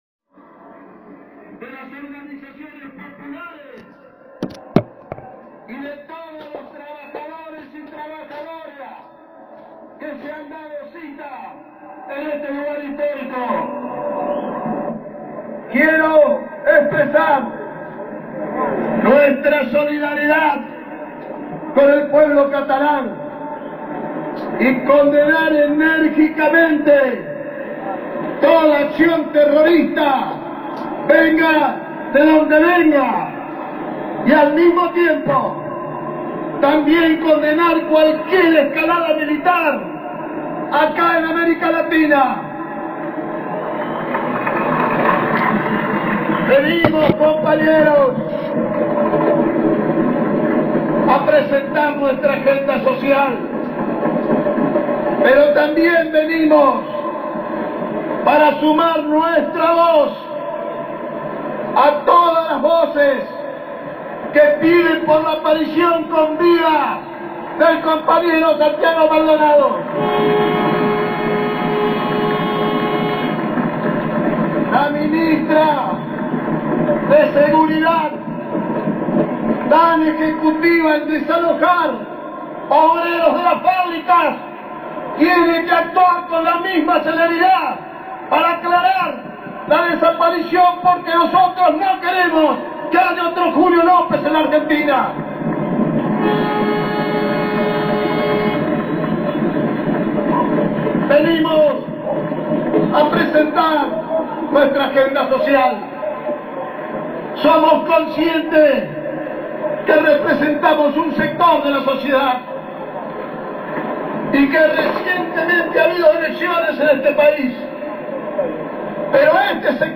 La CGT concluyó una multitudinaria marcha, que arrancó minutos antes de las 15, como estaba previsto, y arengó el próximo Comité Confederal, que se va a llevar a cabo el 25 de septiembre y uno de los puntos a tratar será el próximo paro general.
Discurso completo de Juan Carlos Schmid, secretario general de la CGT.-